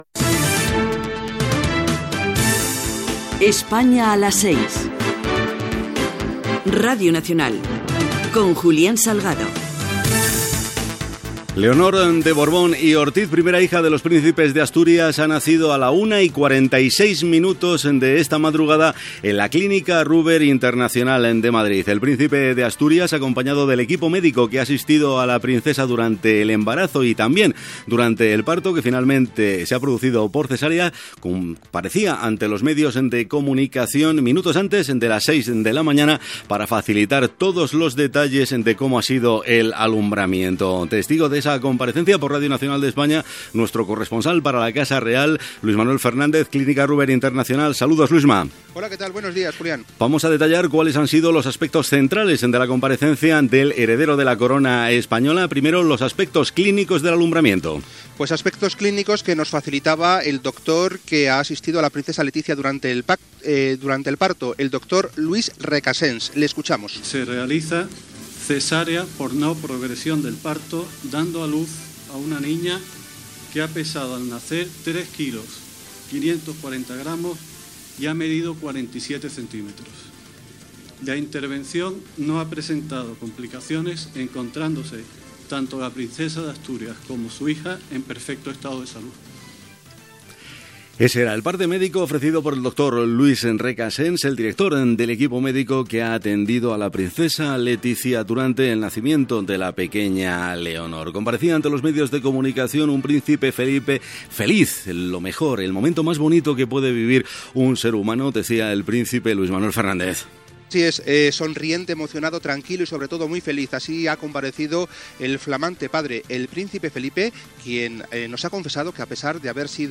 Indiactiu del programa, informació sobre el naixement de la princesa Leonor de Borbón Ortiz.
Informatiu